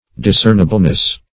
\Dis*cern"i*ble*ness\
discernibleness.mp3